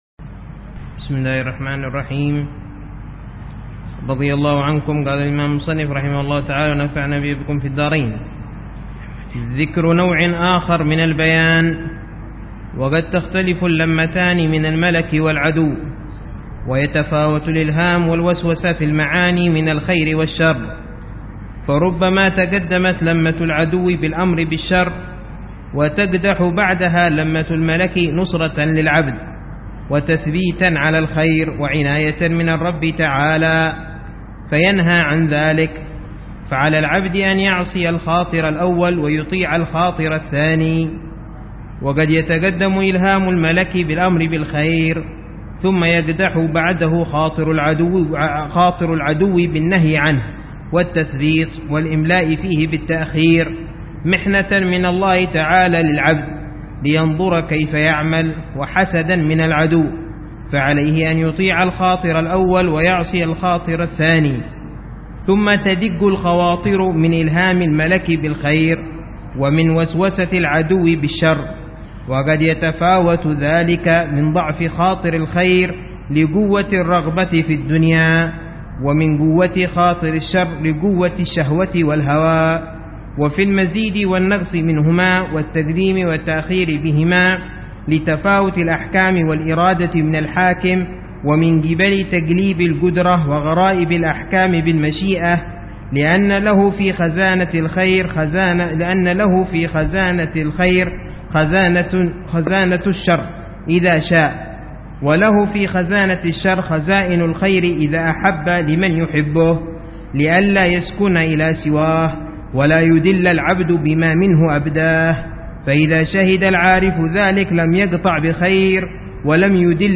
قراءة بتأمل وشرح لمعاني كتاب قوت القلوب للشيخ: أبي طالب المكي ضمن دروس الدورة التعليمية السادسة عشرة بدار المصطفى 1431هجرية.